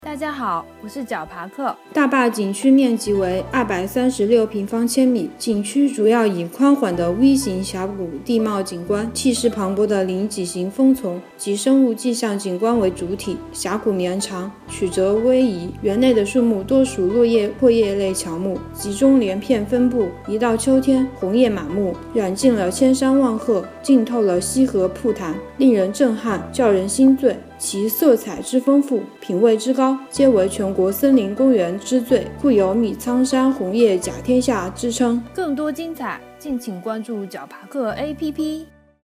大坝景区----- fin 解说词: 大坝景区面积为236平方千米，景区主要以宽缓的“V”形峡谷地貌景观、气势磅礴的岭脊型峰丛及生物季相景观为主体，峡谷绵长，曲折逶迤。